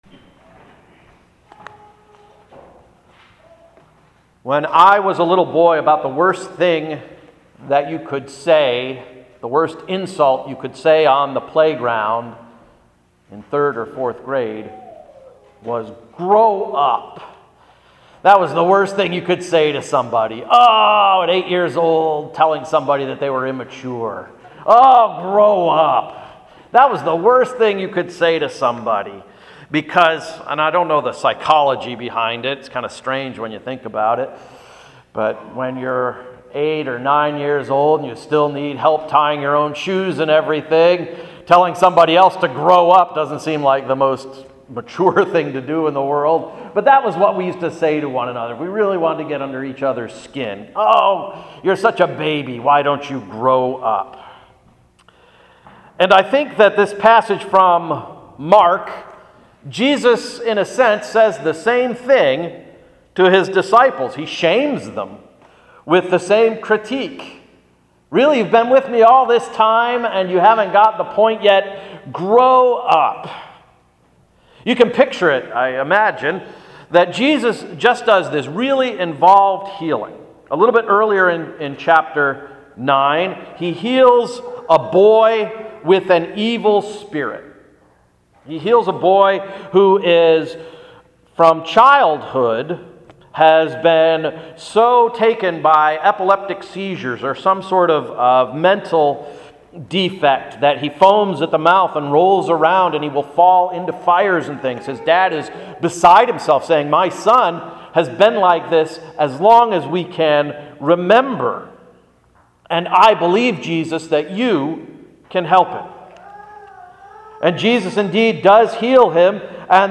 Sermon of September 20, 2015–“Grow Up!”